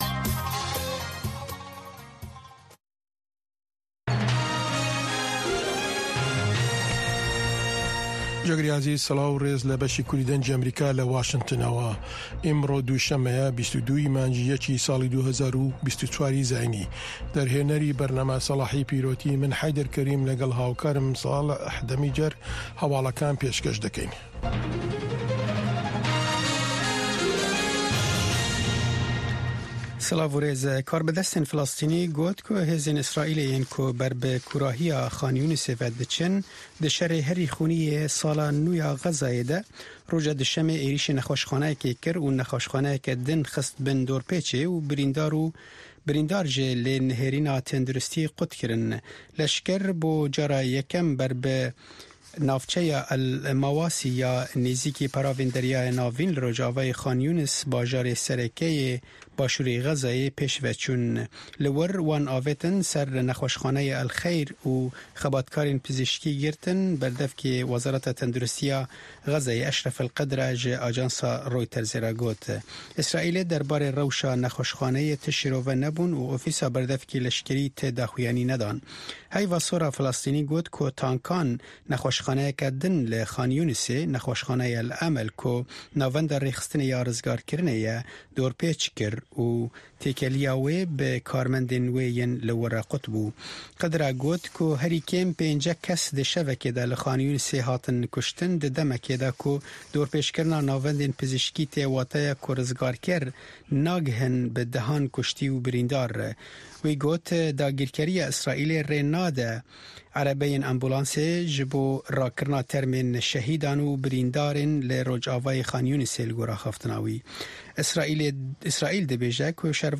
هەواڵە جیهانیـیەکان لە دەنگی ئەمەریکا